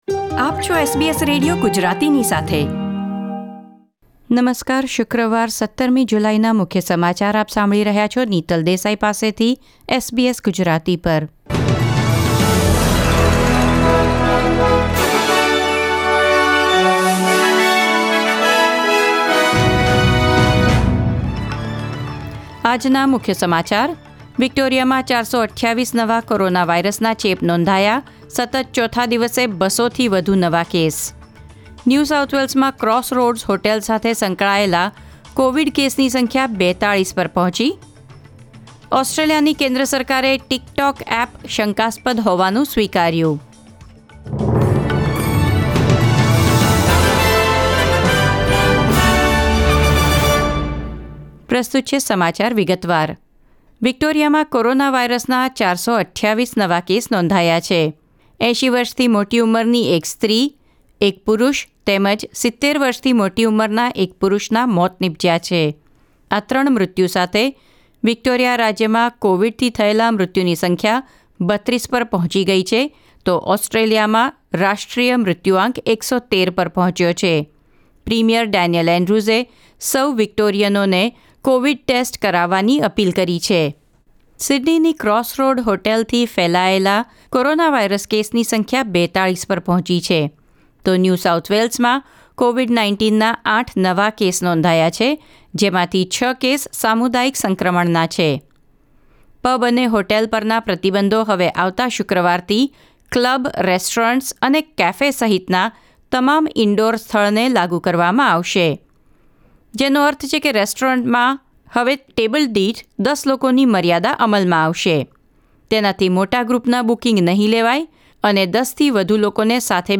SBS Gujarati News Bulletin 17 July 2020